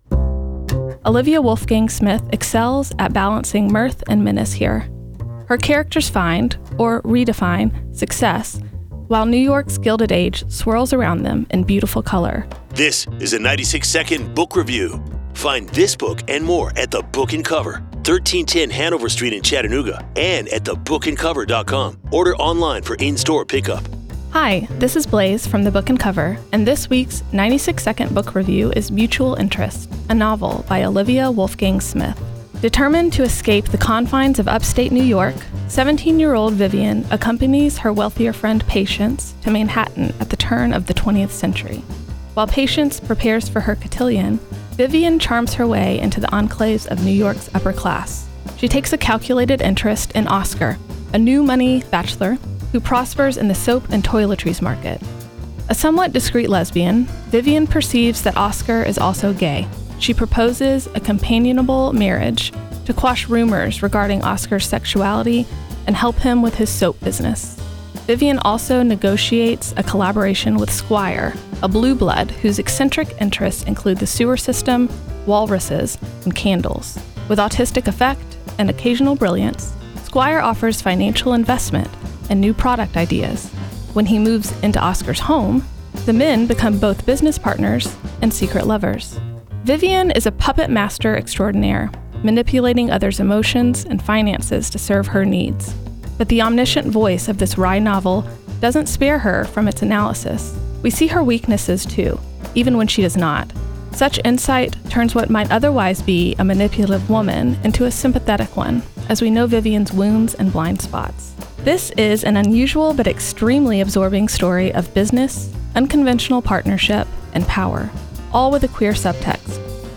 96-Second-Book-Review-Mutual-Interests-Book-and-Cover-.wav